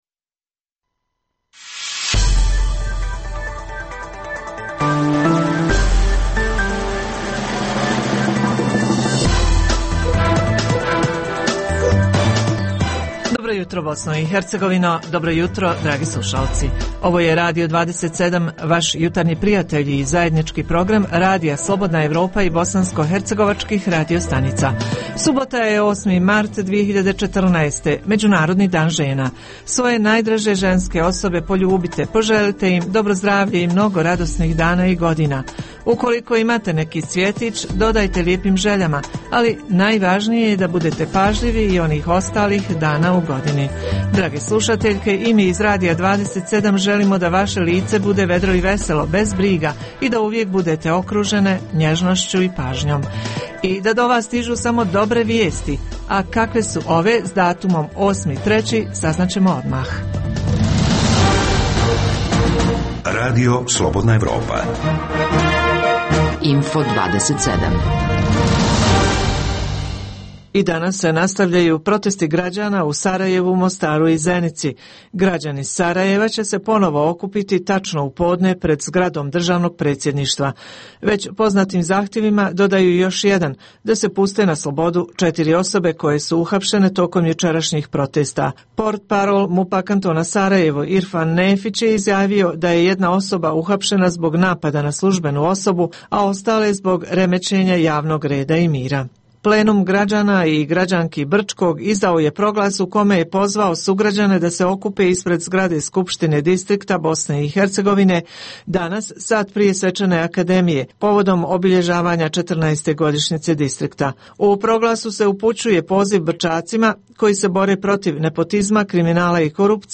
Kakvi su turistički potencijali Zvornika, Počitelja kod Mostara, Travnika i Brčkog? - Uz tri pregleda vijesti, slušaoci mogu uživati i u ugodnoj muzici.